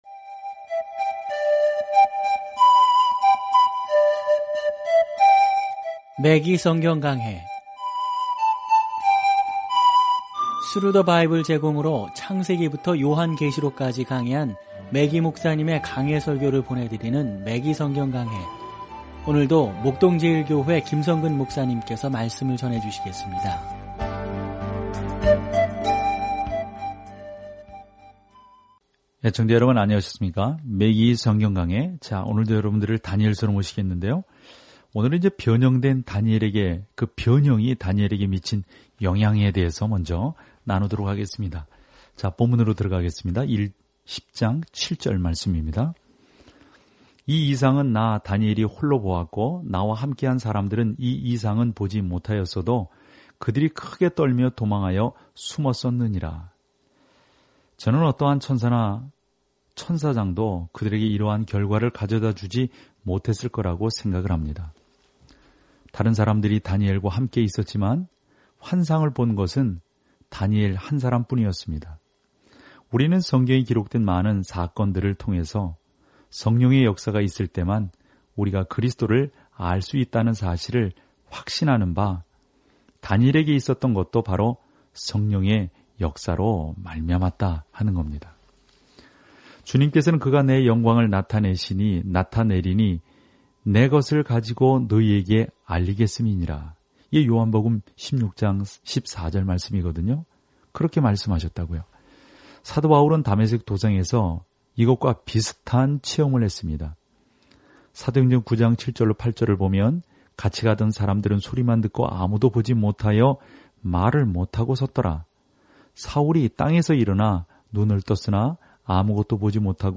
말씀 다니엘 10:7-13 24 묵상 계획 시작 26 묵상 소개 다니엘서는 하나님을 믿었던 한 사람의 전기이자, 결국 세상을 통치하게 될 사람에 대한 예언적 환상입니다. 오디오 공부를 듣고 하나님의 말씀에서 선택한 구절을 읽으면서 다니엘을 통해 매일 여행하십시오.